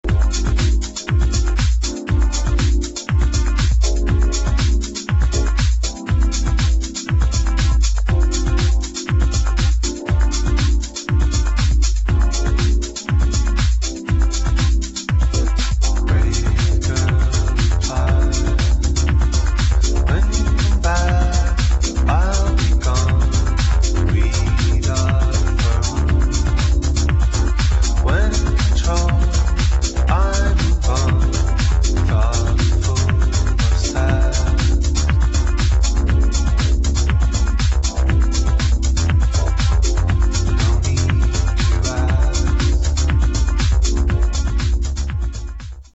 [ HOUSE / ELECTRO POP ]